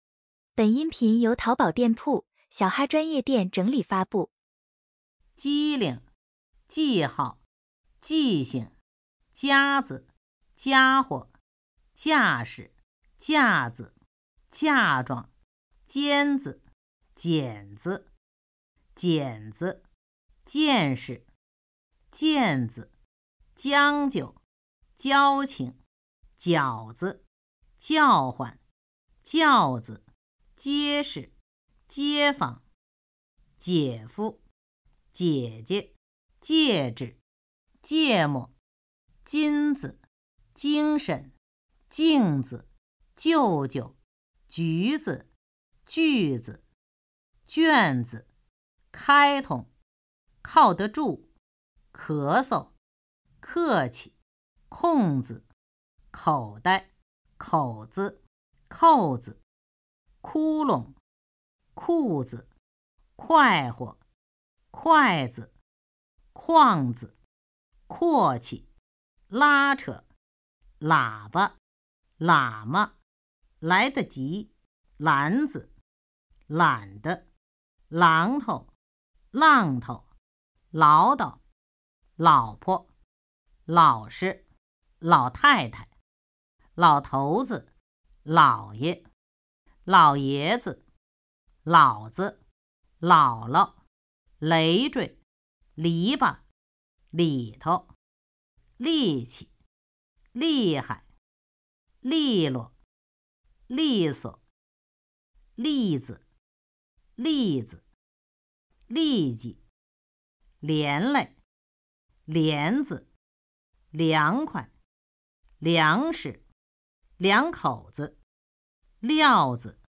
轻声201到300.mp3
普通话水平测试 > 普通话水平测试资料包 > 01-轻声词语表